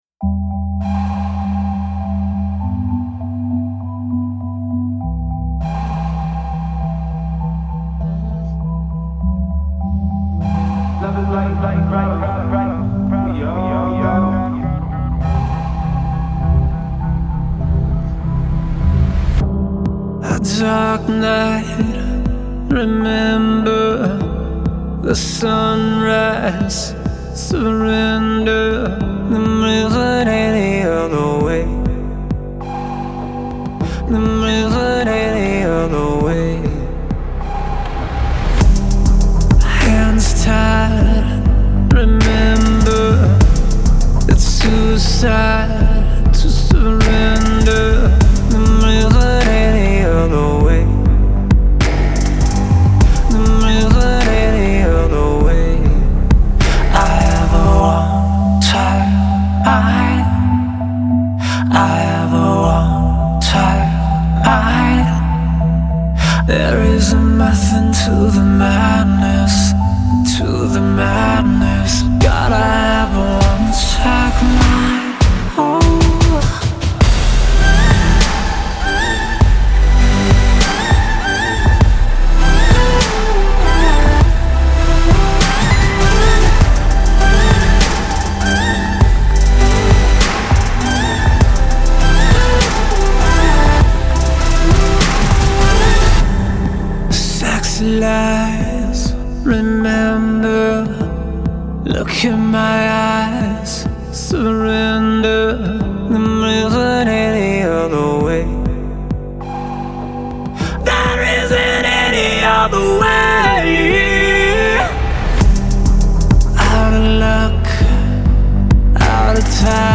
сочетающая элементы рок-музыки и хип-хопа.